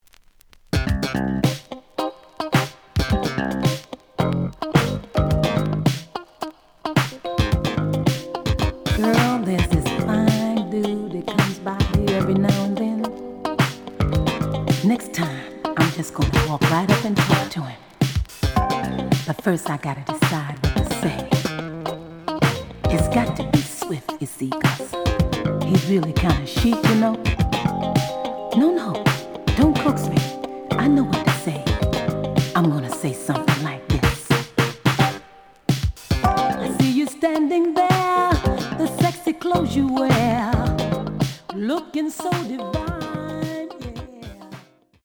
The audio sample is recorded from the actual item.
●Format: 7 inch
●Genre: Disco